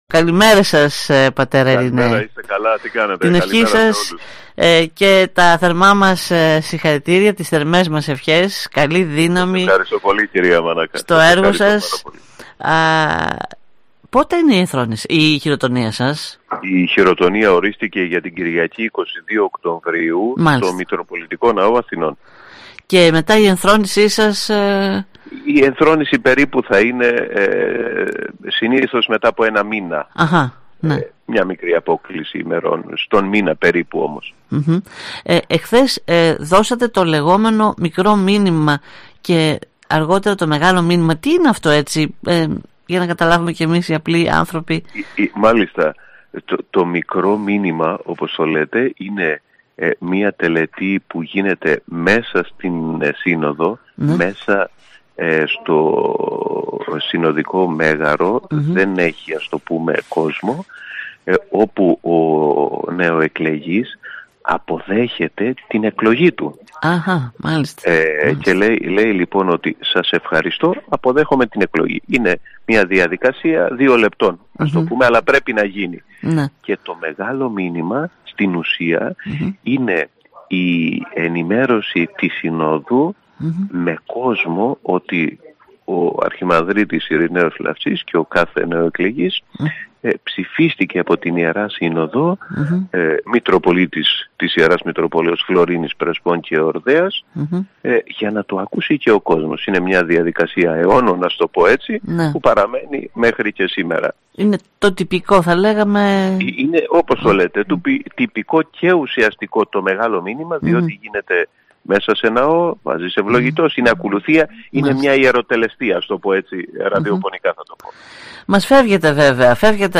Ιδιαίτερα συγκινημένος μίλησε στην ΕΡΤ Ορεστιάδας ο μέχρι σήμερα Πρωτοσύγκελος της Ιεράς Μητροπόλεως Αλεξανδρουπόλεως π. Ειρηναίος  Λαφτσής μετά την πρόσφατη εκλογή του από την Ιερά Σύνοδο της Εκκλησίας της Ελλάδος ως Μητροπολίτη Φλωρίνης Πρεσπών και Εορδαίας